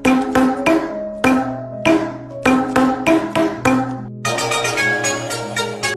kitaiskaia pesnia trubami Meme Sound Effect
kitaiskaia pesnia trubami.mp3